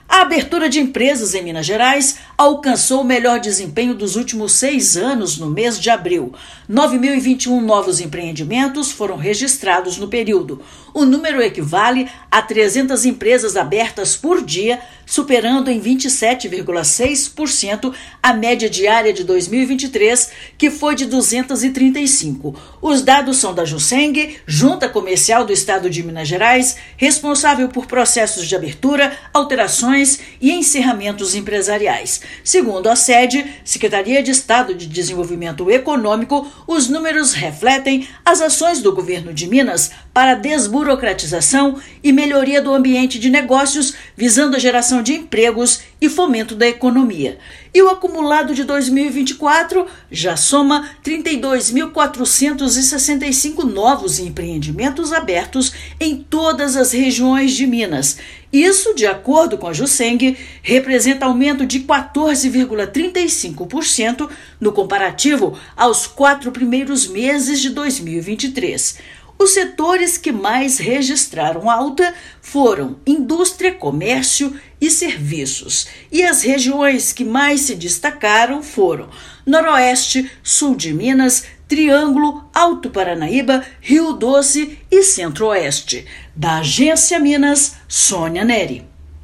[RÁDIO] Minas Gerais volta a bater recorde com 300 empresas abertas por dia em abril
Novos negócios registrados em todo o estado superaram em mais de 44% o volume verificado no mesmo período do ano anterior. Ouça matéria de rádio.